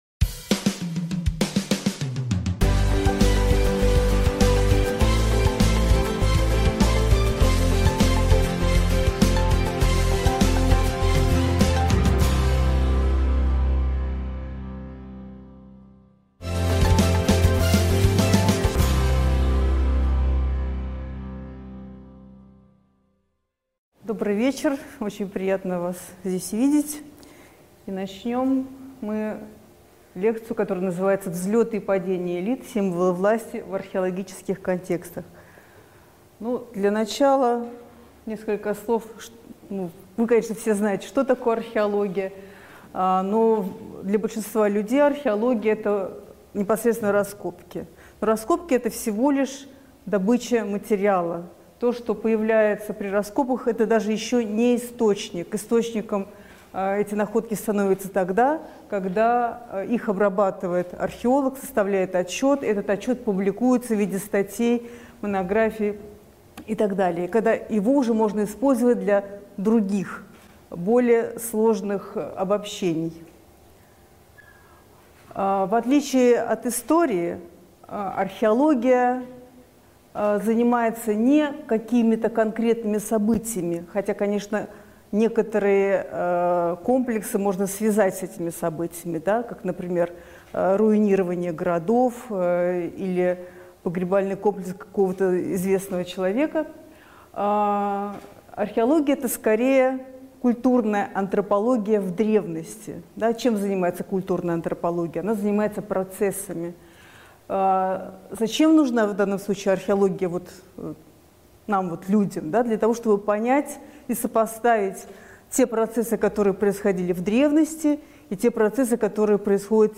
Аудиокнига Взлеты и падения элит. Символы власти в археологических контекстах | Библиотека аудиокниг